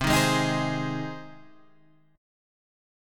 Cm#5 chord {8 6 6 8 9 8} chord